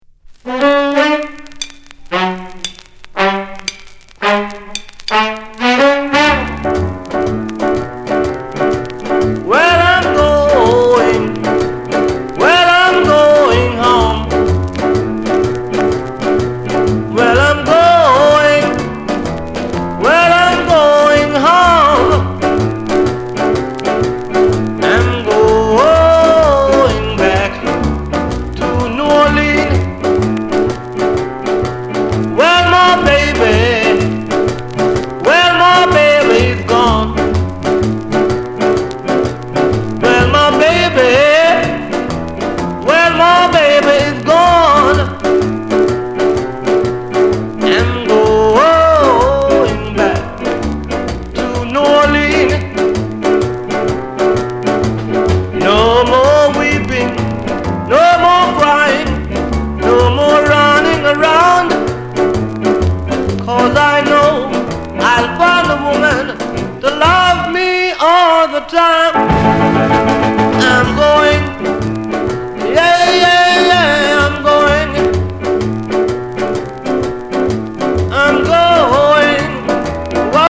Genre: Rhythm & Blues